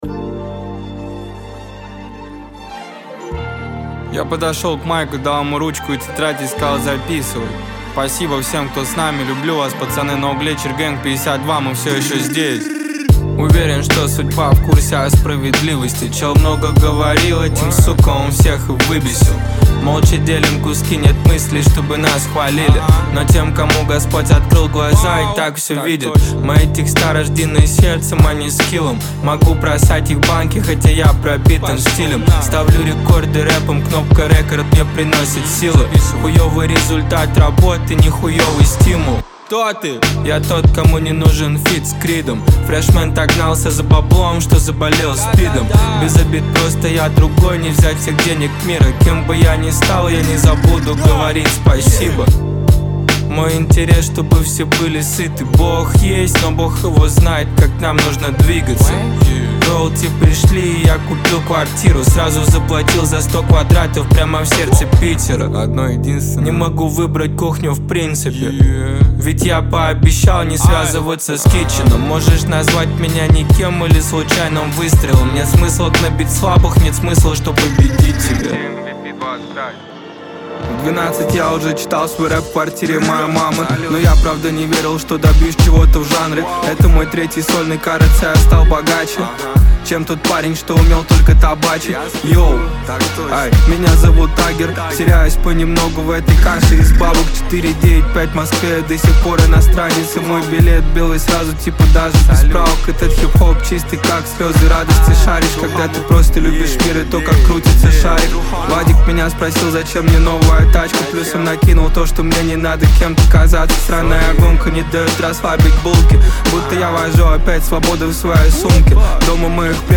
Трек размещён в разделе Русские песни / Детские песни.